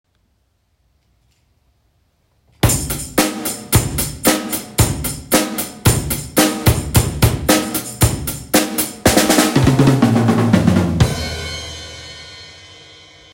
Lyömäsoittimet